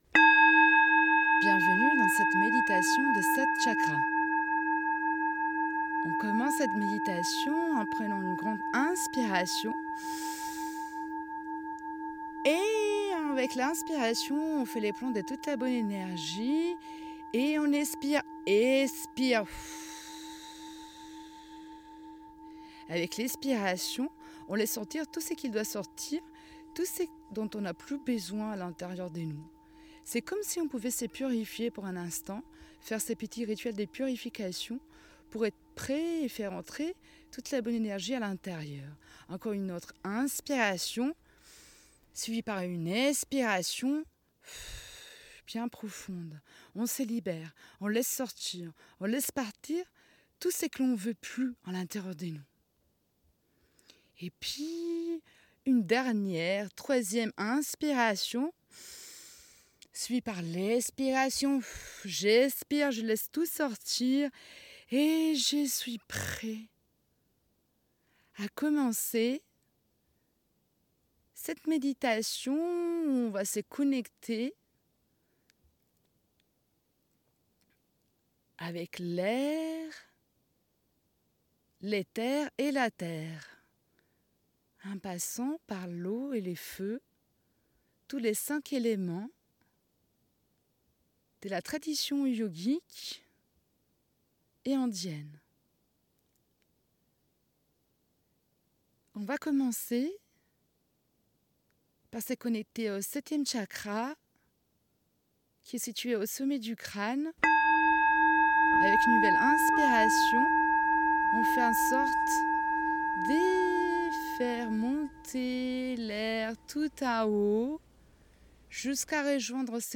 Voici le Podcast de la méditation des 7 chakras en version intégrale.